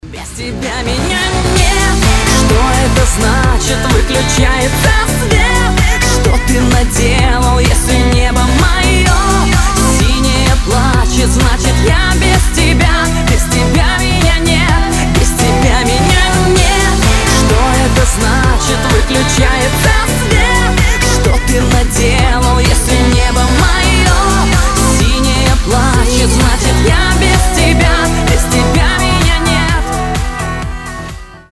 громкие
женский голос